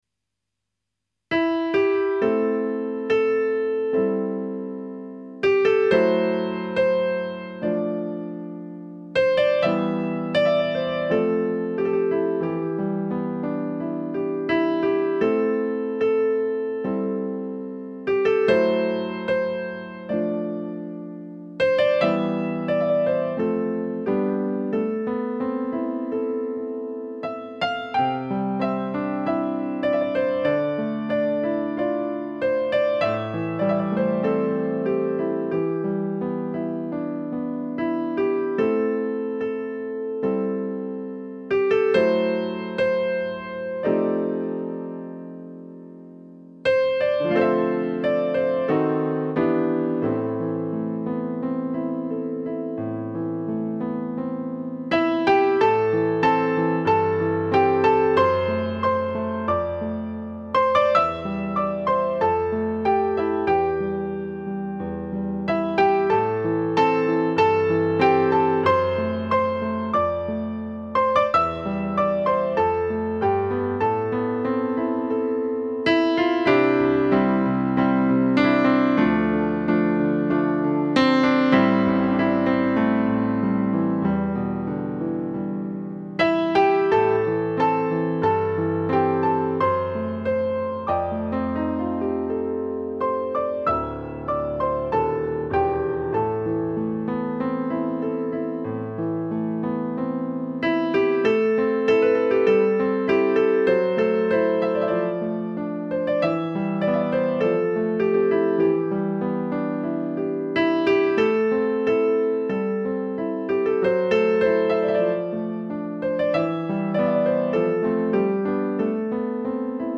Simple version
(Piano or Kbd)   mp3 Audio only Full arrangement